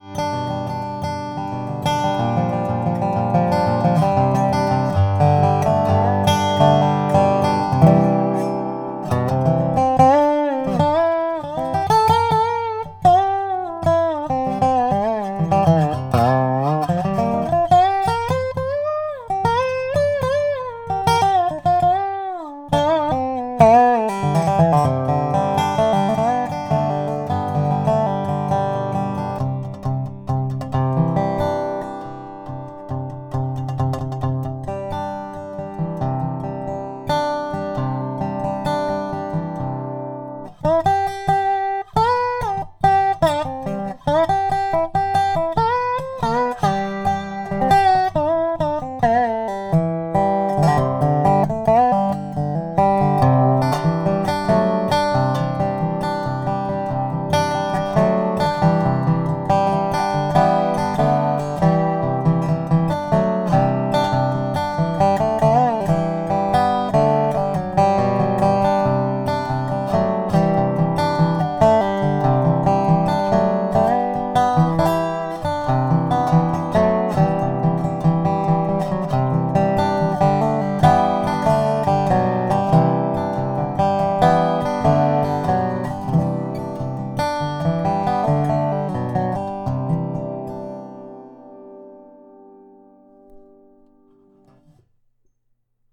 Nástroj vyrobený z celomasivního fládrovaného javoru s velmi silným a vyrovnaným zvukem, získaným díky pečlivému vybírání komponent a materiálů.
Zvuk je dynamicky vyvážený, barevně prokreslený a silný, s velmi dlouhým dozvukem.